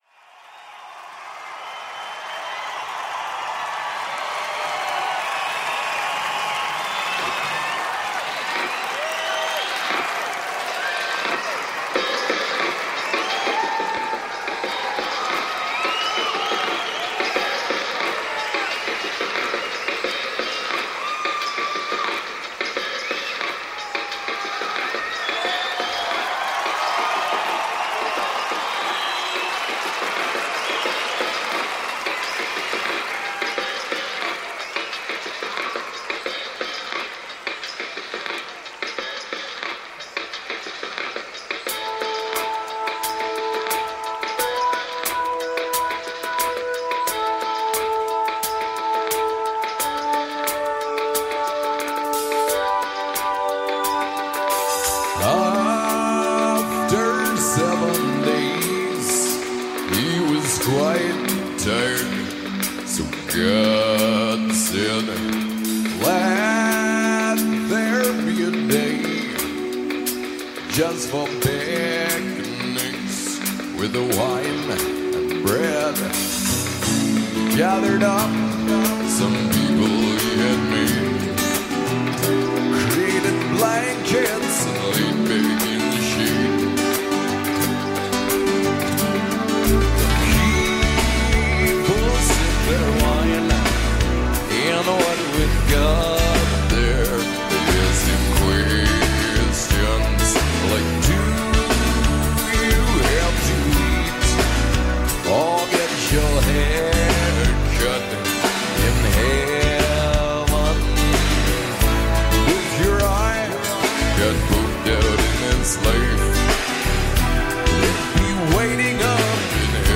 The post-Punk/Folk-Punk aggregation from Manitoba up north
Post-punk/Folk-Punk